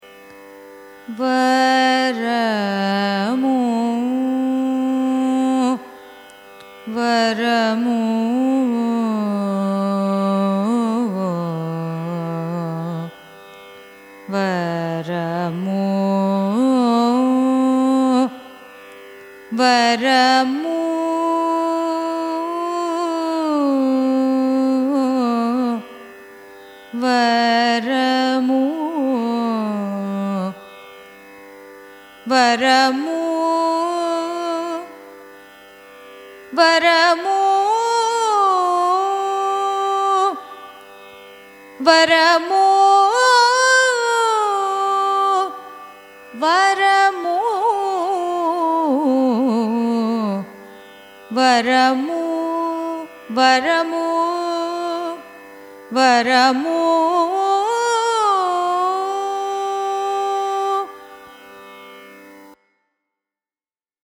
Raga Varamu
Janyam of 22nd mela Karaharapriya.
raga-varamu-signature.mp3